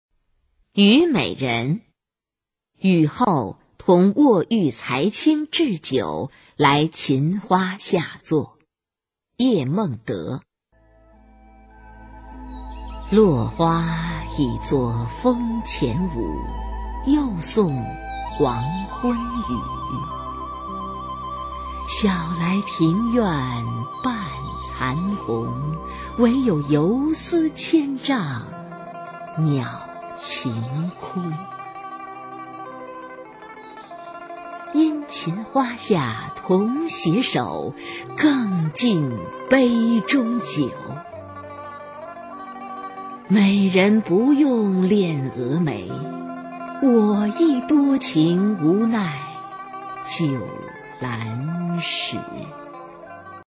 [宋代诗词诵读]叶梦得-虞美人 宋词朗诵